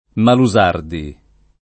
Malusardi [ malu @# rdi ] cogn.